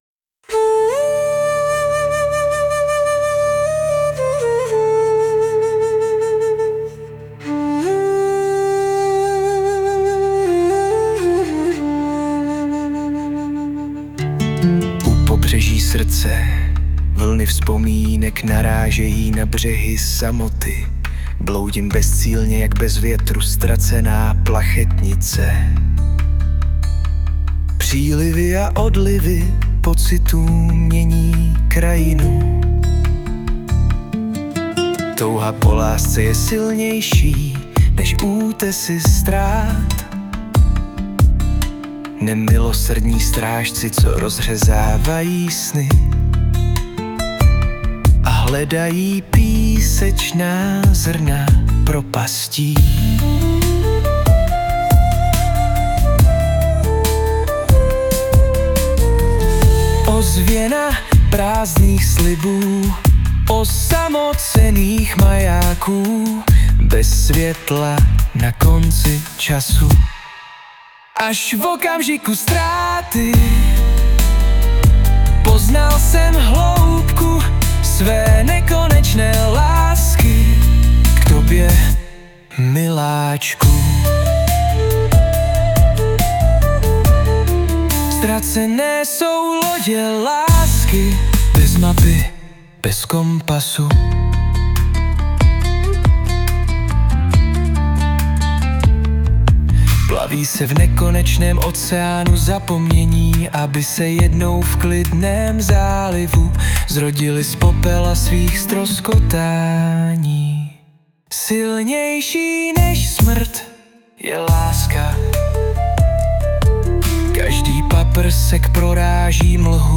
2025 & Hudba, zpěv a obrázek: AI
ale zrovna u téhle mi vůbec nesedí ty hlasy a projev
Při písních od AI je to někdy složité. Všiml jsem si, že při českých slovech má docela dost často problém vyslovit správně některá slova.